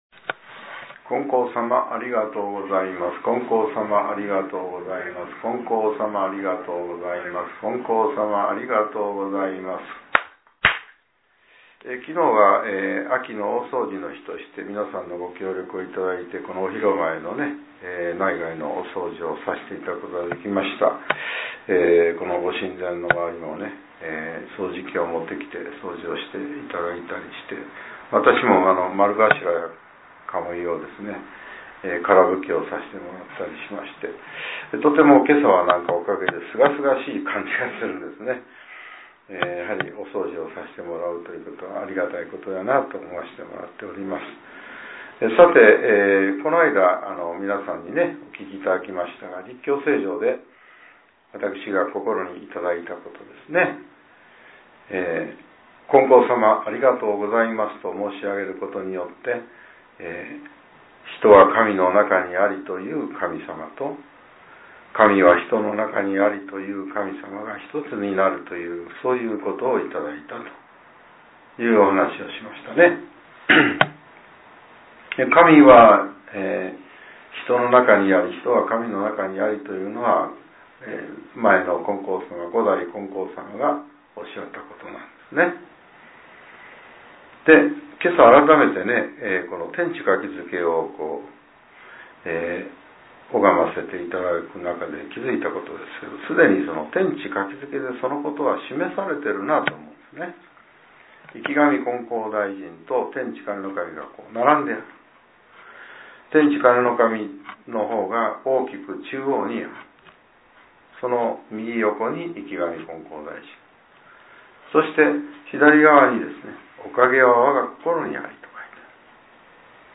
令和６年１１月３日（朝）のお話が、音声ブログとして更新されています。